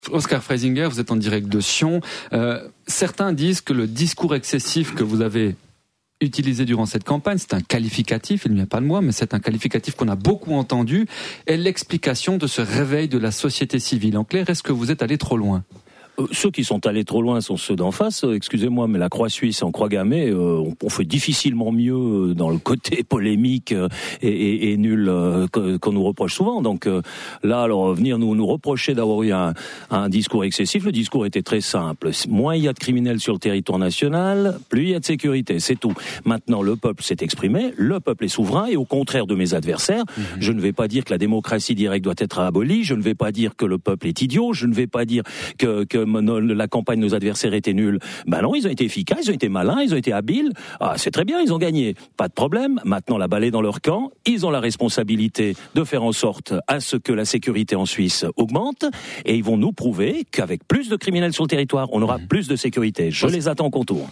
Aujourd'hui, Jean-Claude Biver, responsable du pôle horloger du groupe LVHM était invité au Journal du matin de la RTS.